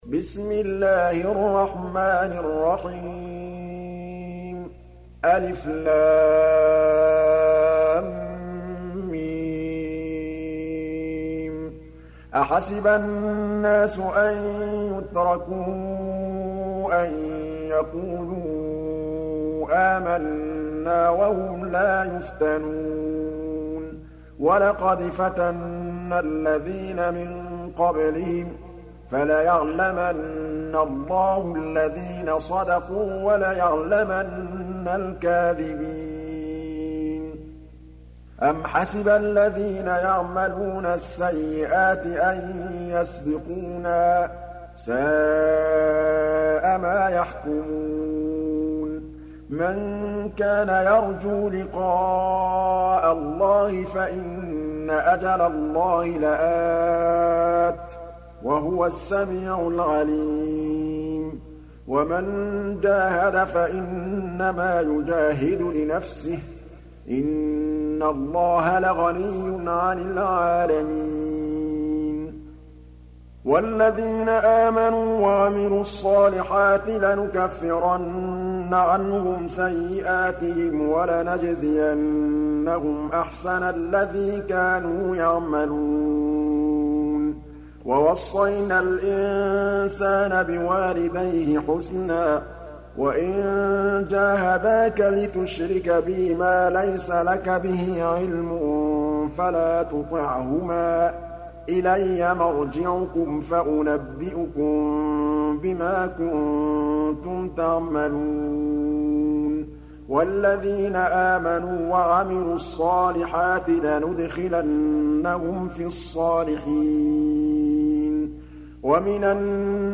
29. Surah Al-'Ankab�t سورة العنكبوت Audio Quran Tarteel Recitation
Surah Sequence تتابع السورة Download Surah حمّل السورة Reciting Murattalah Audio for 29. Surah Al-'Ankab�t سورة العنكبوت N.B *Surah Includes Al-Basmalah Reciters Sequents تتابع التلاوات Reciters Repeats تكرار التلاوات